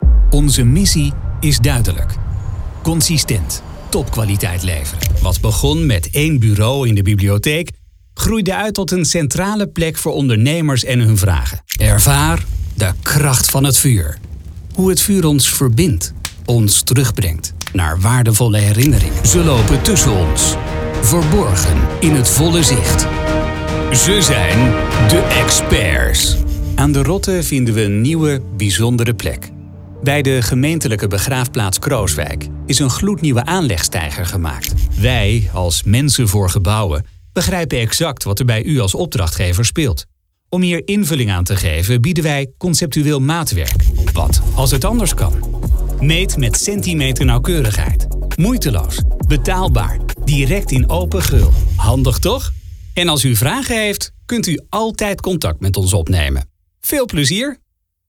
Authentic Dutch voice-over with a warm tone, fast delivery and a relaxed, professional approach
[Dutch] Narration for corporate films and online videos
Middle Aged